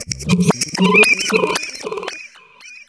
FXREVERSE4-L.wav